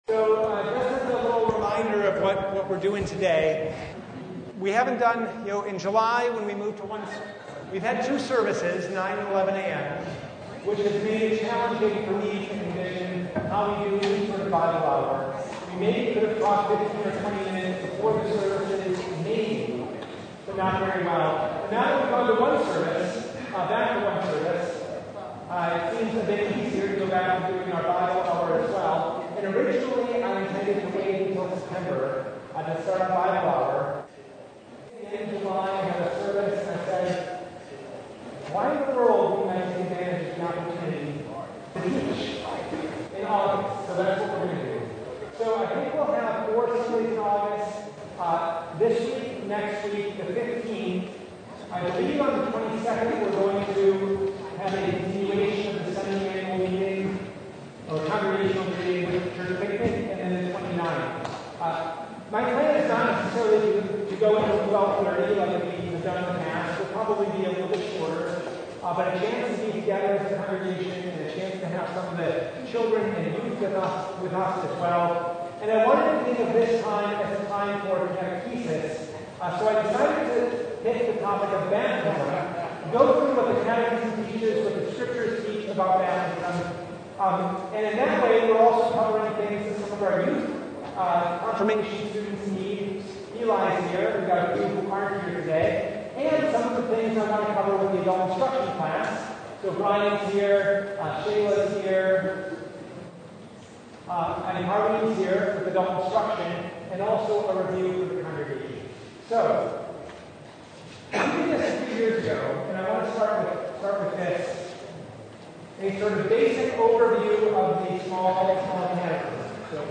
Matthew 28:18-20 Service Type: Bible Study Topics